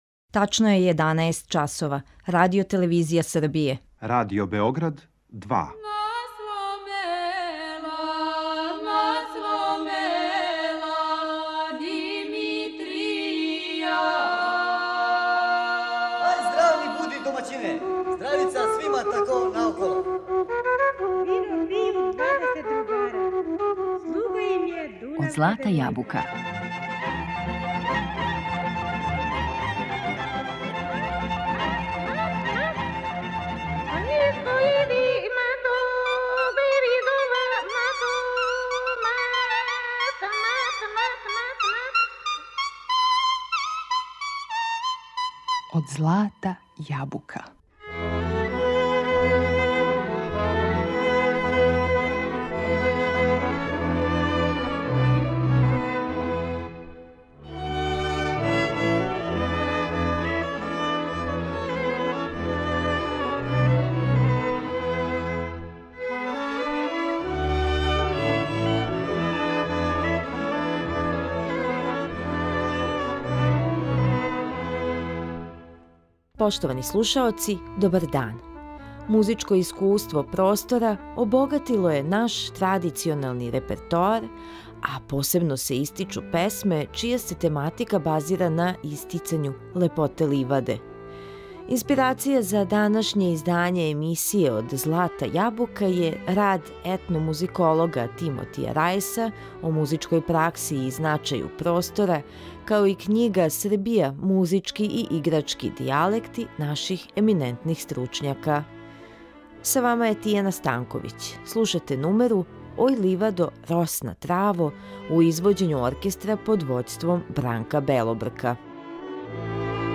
У данашњој емисији Од злата јабука преслушавамо песме пасторалне тематике.
Музичко искуство простора обогатило је наш традиционални репертоар а посебно су лепе песме чија се љубавна тематика лоцира на ливади.